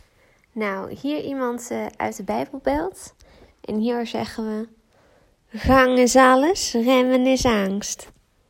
Deze knorrende kat kwam mij zojuist wakker maken :heart_eyes_cat: